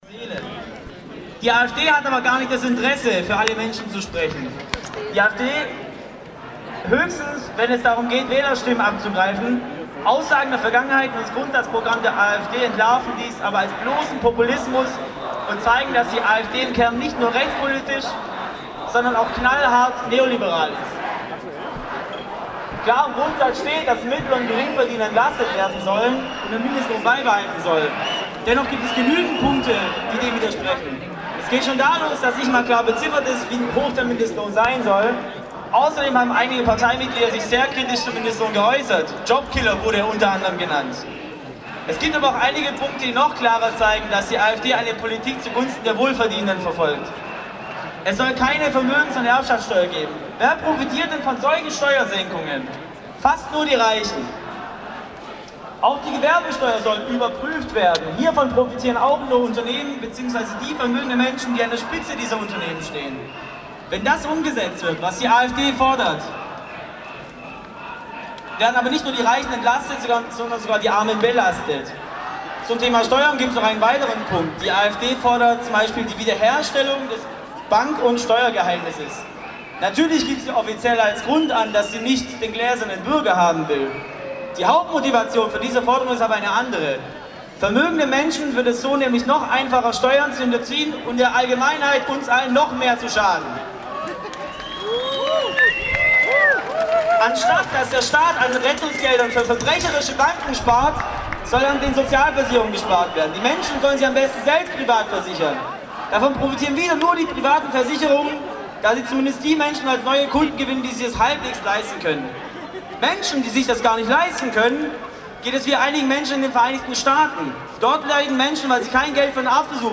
Weiter unten finden sich Redebeiträge von: